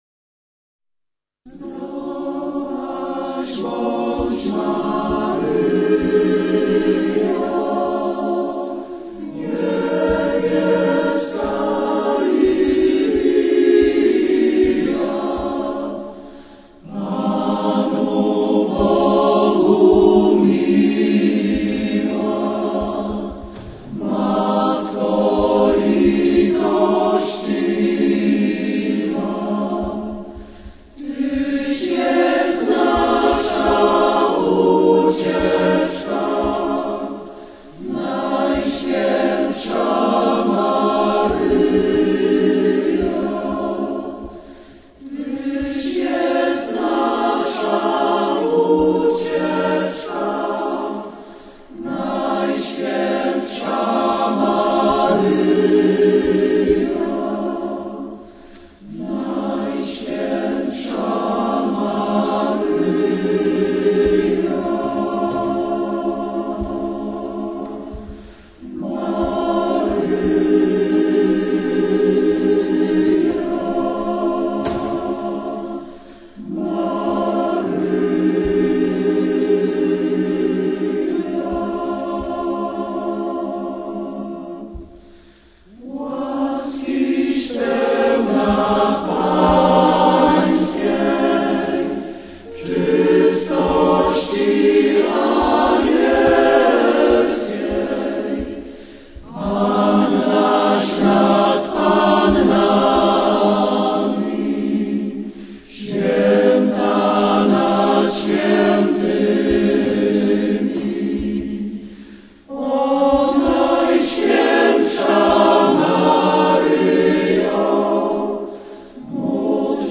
wersja koncertowa